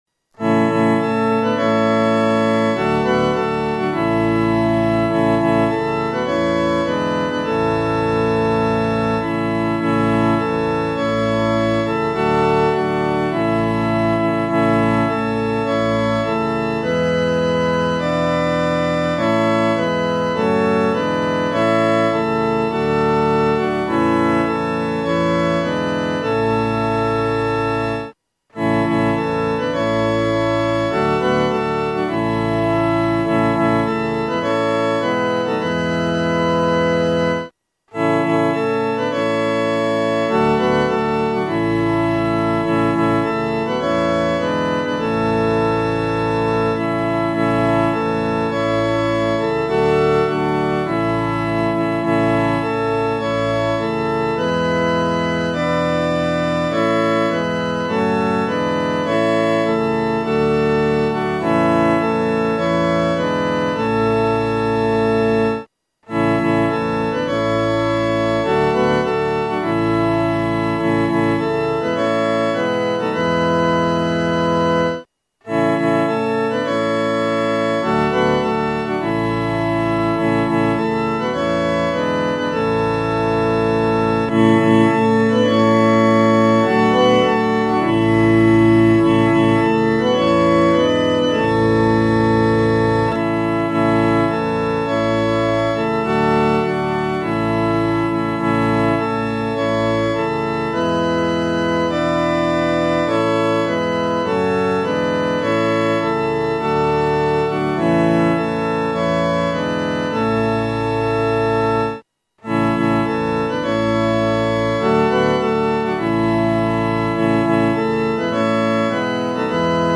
伴奏
四声
本首圣诗由网上圣诗班 (环球）录制
《颂赞主圣名歌》的正歌曲调比较平稳，但副歌曲调兴奋、活跃。